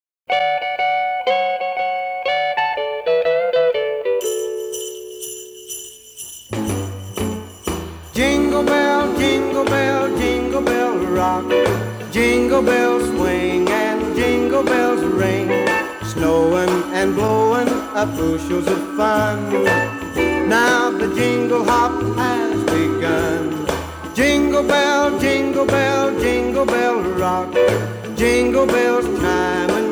• Holiday
was an American country music singer